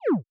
SFX_Dialog_Close_01.wav